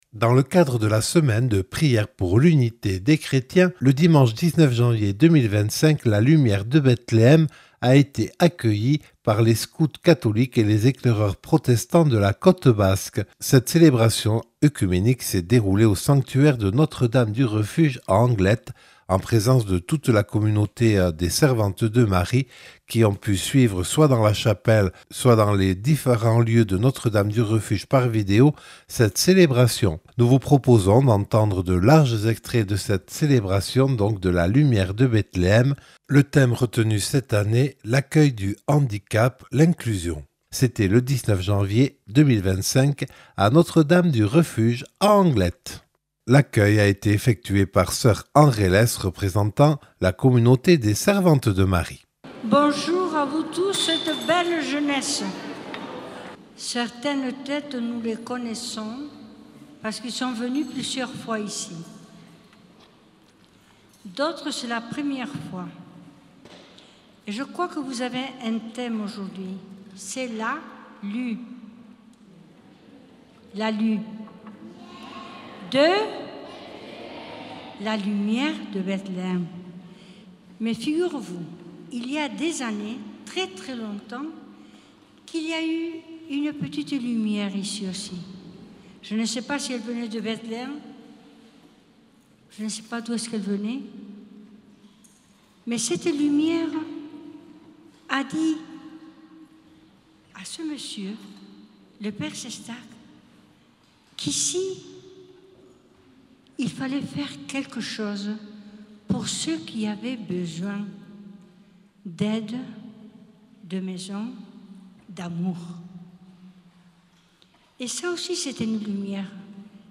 Plus d’une centaine de Scouts catholiques et Eclaireurs protestants ont accueilli la lumière de Bethléem lors d’une célébration oecuménique le 19 janvier 2025 à Notre-Dame du Refuge à Anglet dans le cadre de la Semaine de prière pour l’unité des Chrétiens : écouter la célébration.